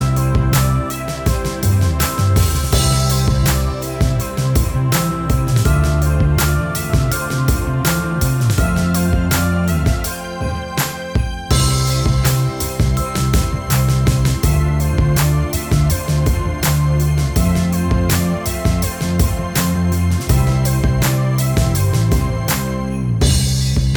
Minus All Guitars Pop (2010s) 3:55 Buy £1.50